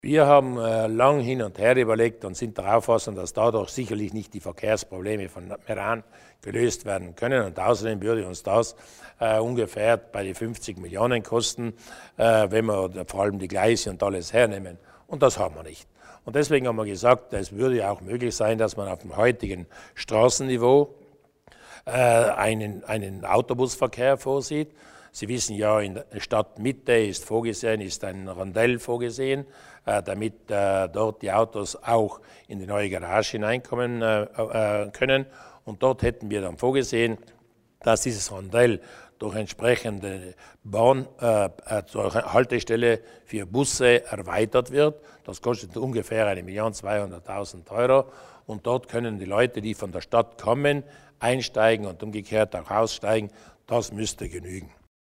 Landeshauptmann Durnwalder zu den Details der Meraner Umfahrung